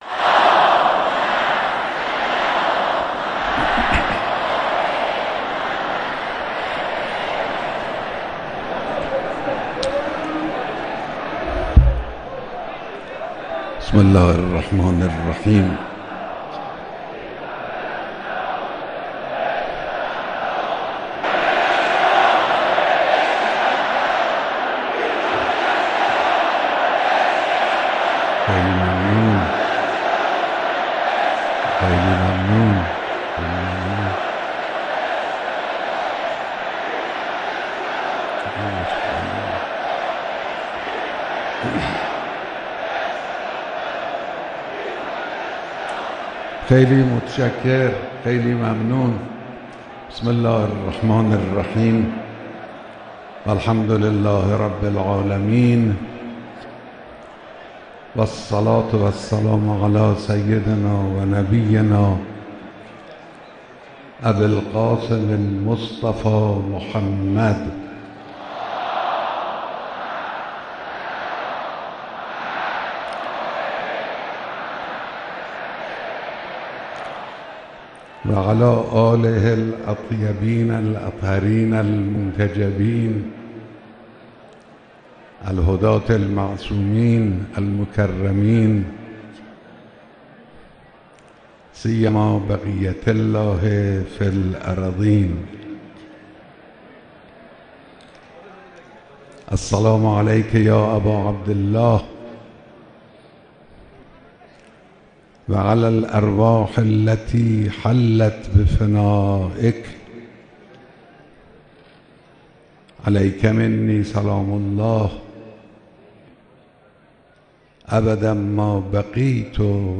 سخنرانی در همایش ده‌ها هزارنفری «خدمت بسیجیان» در ورزشگاه آزادی
بیانات رهبر انقلاب در همایش ده‌ها هزارنفری «خدمت بسیجیان» در ورزشگاه آزادی